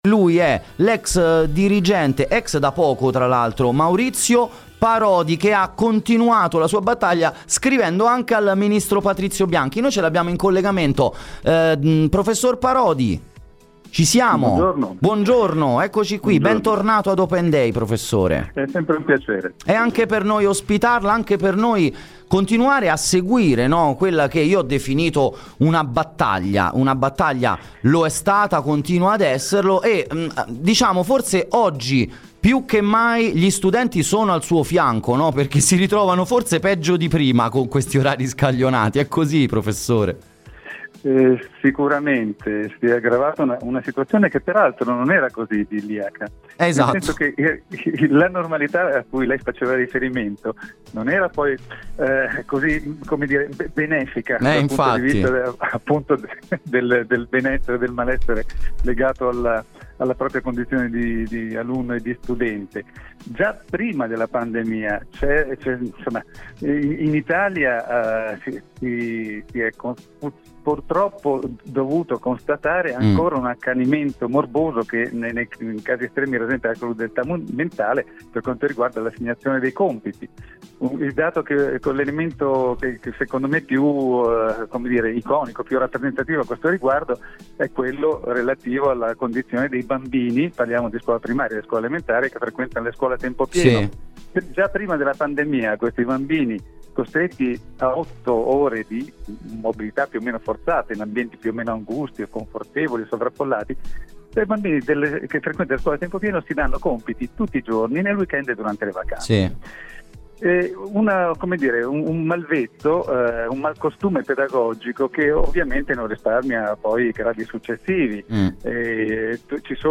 intervistacusanocampus.mp3